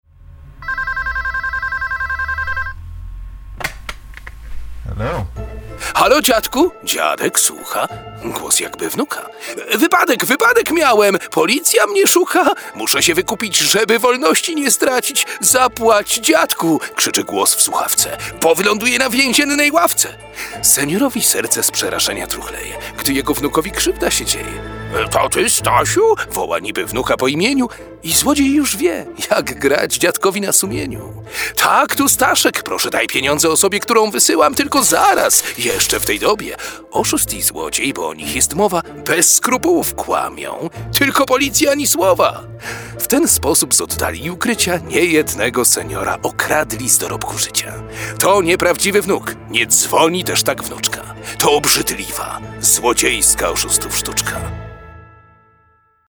Specjalnie na tę wyjątkową okazję chojniccy policjanci prewencji, w ramach działań profilaktycznych, wspólnie z Radiem Weekend FM przygotowali radiowy spot skierowany do seniorów. Przestrzegają w nich jak działają oszuści podszywający się pod bliskich lub samych policjantów. Policyjne dykteryjki odczytał jeden z najbardziej znanych, polskich lektorów Pan Rino Pawletta.